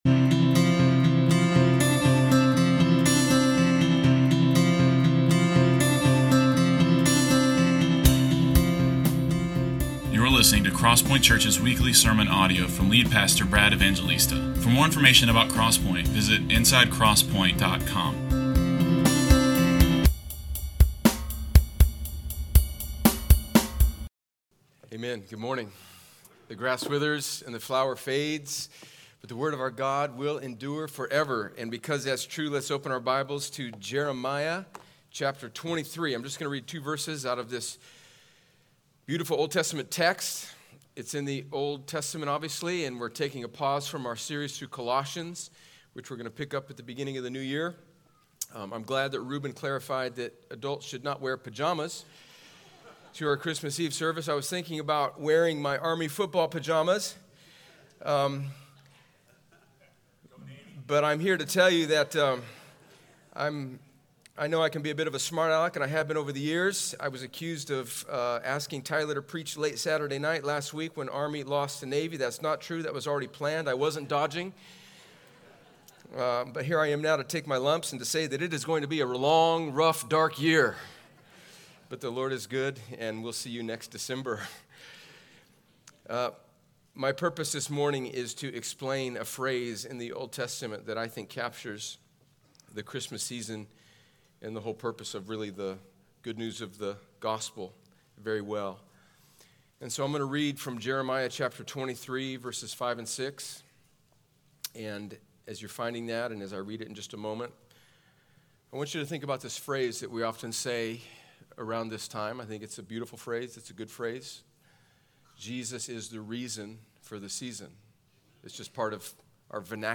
The sermons of CrossPointe Church in Columbus, Ga.